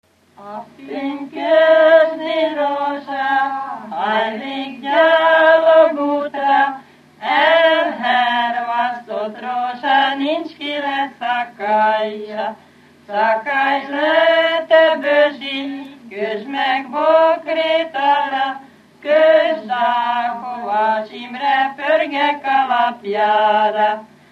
Felföld - Bars vm. - Aha
Műfaj: Párosító
Stílus: 7. Régies kisambitusú dallamok